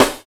113 SNARE.wav